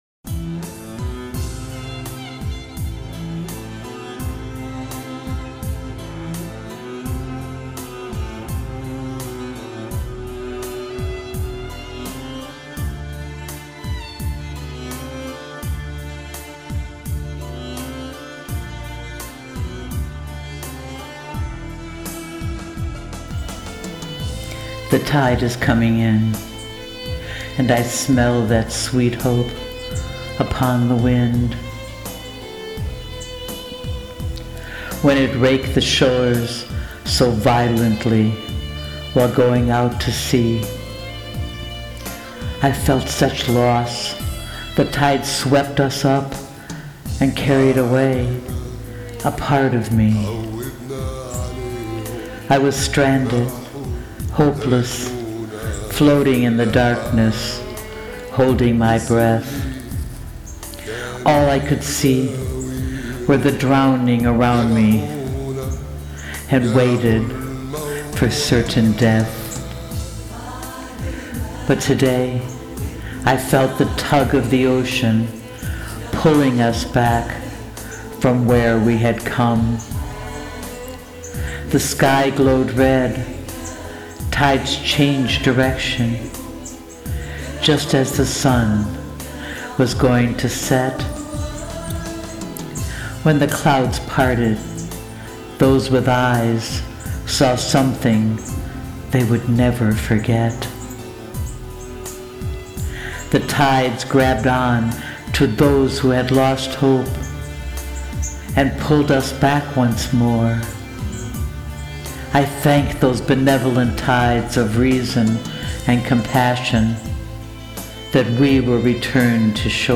Please Press Play to hear me recite my poem to the music